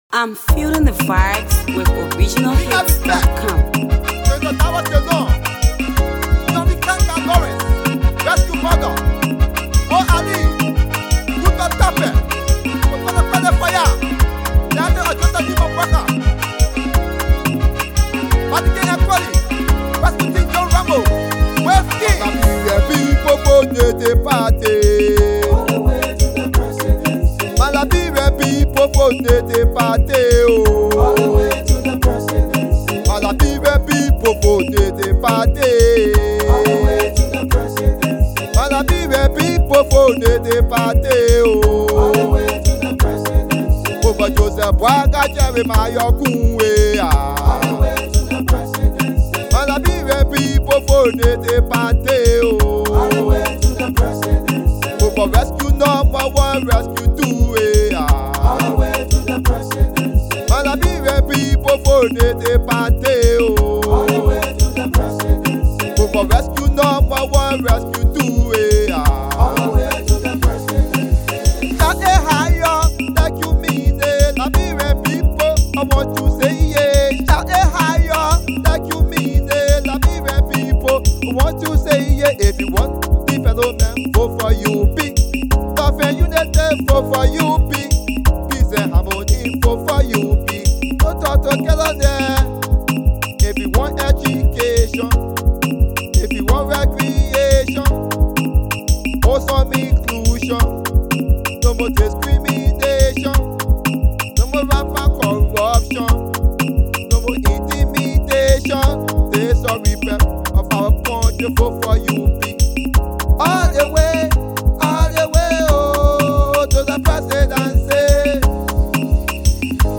Campaign Song